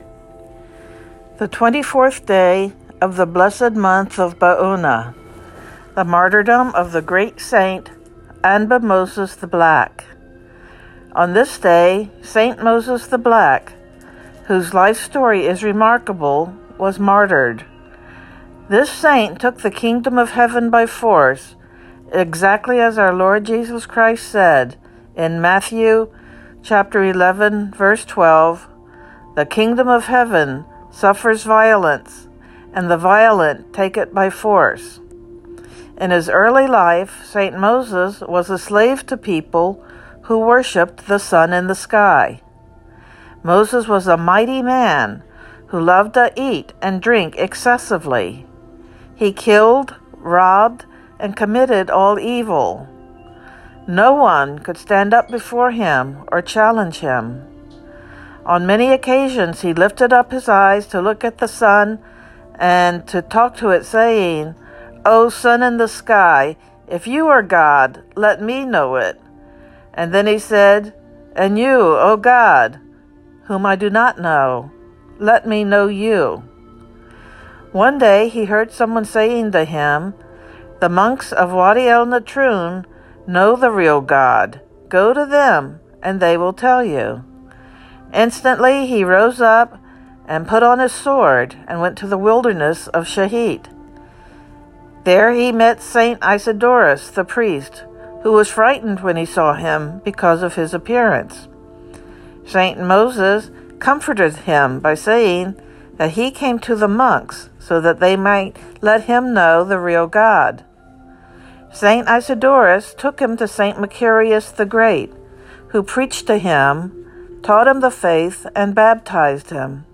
Synaxarium readings for the 24th day of the month of Baounah